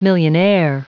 Prononciation du mot millionaire en anglais (fichier audio)
Prononciation du mot : millionaire